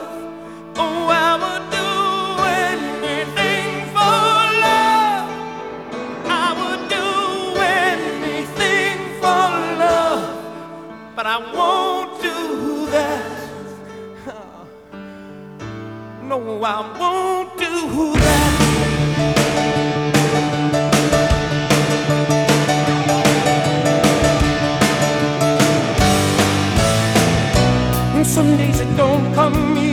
• Rock